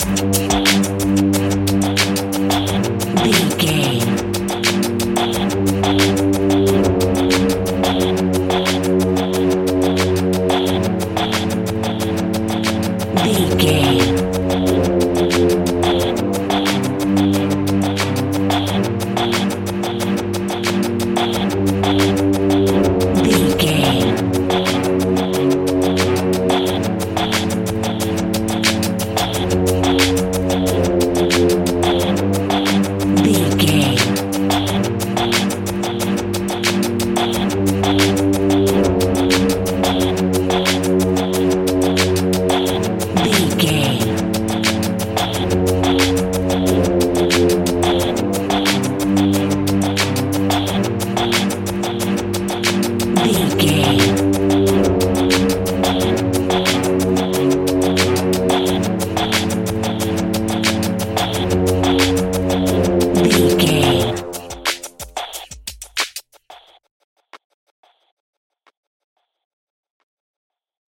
In-crescendo
Thriller
Aeolian/Minor
scary
ominous
dark
haunting
eerie
strings
brass
percussion
violin
cello
double bass
cymbals
gongs
taiko drums
timpani